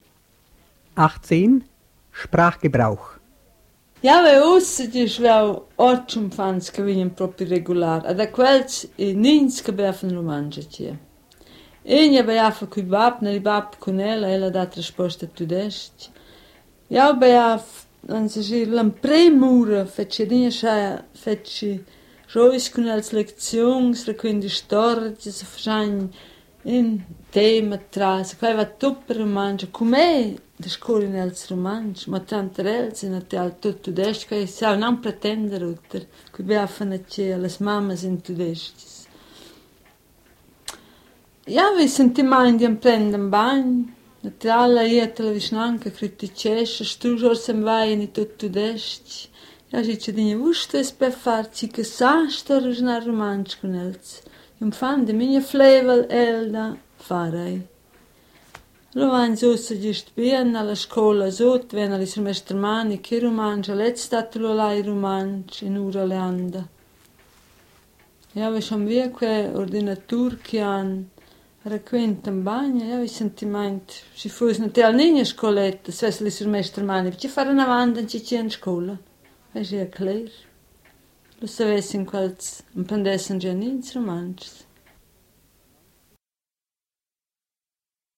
in the Romansh idioma Sutsilvan
Phonogram Archive, Zurich